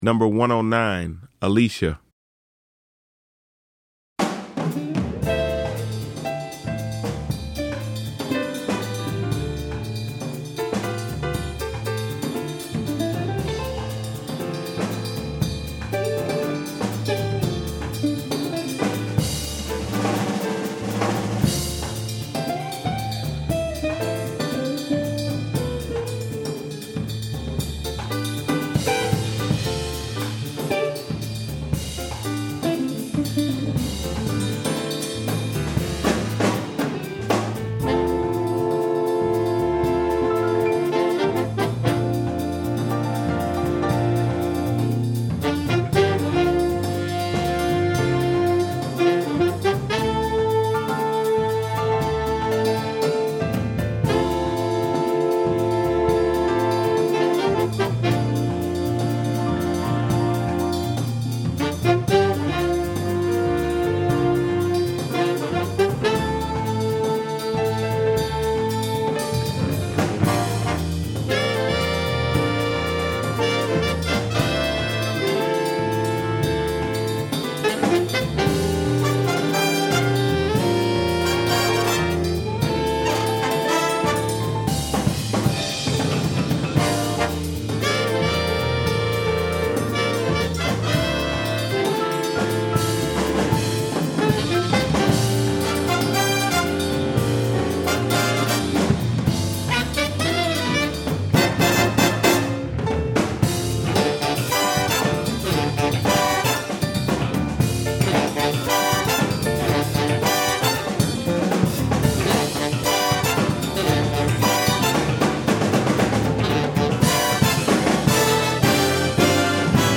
• 5 Saxophones
• 4 Trumpets
• 4 Trombones
• Vibraphone
• Guitar
• Piano
• Bass
• Drums